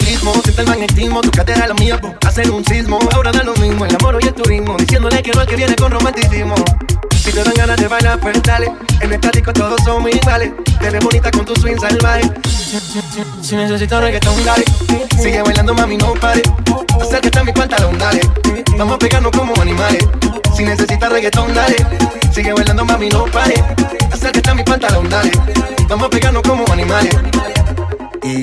Genere: latin, reggaeton